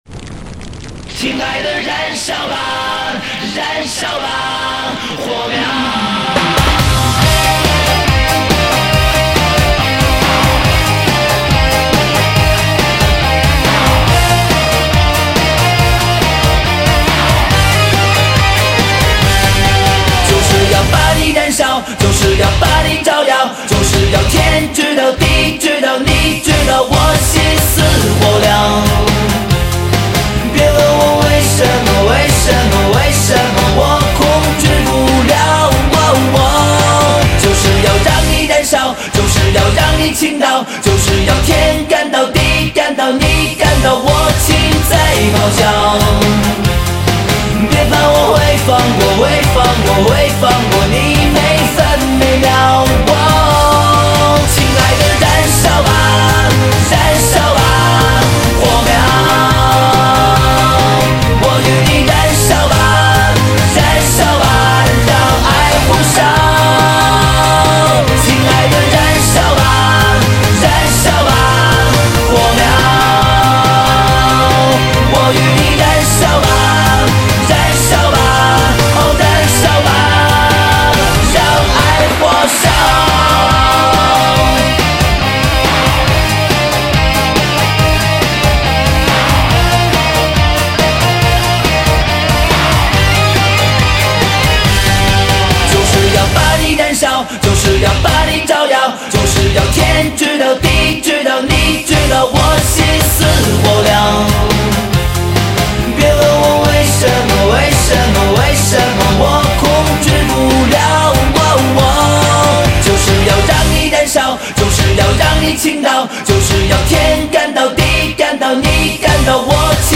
顺畅的旋律、喧闹的节奏卷土重来